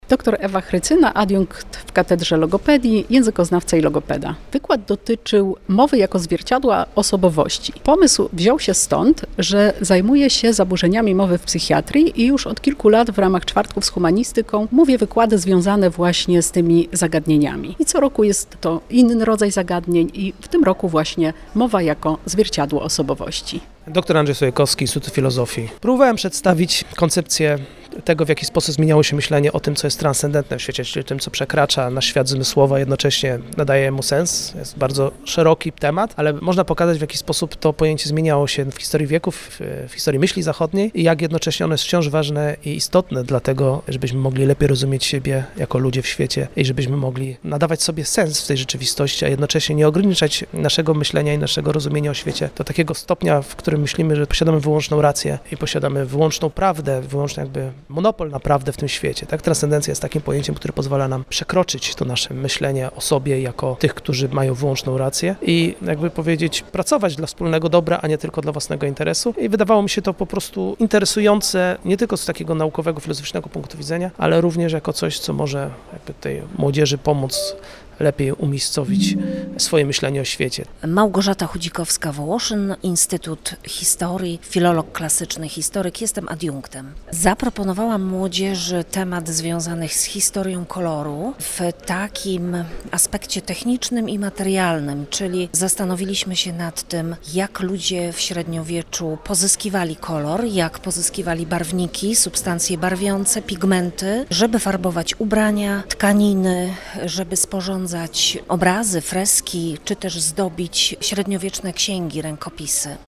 rozmawiała także z trójką autorów styczniowych prelekcji.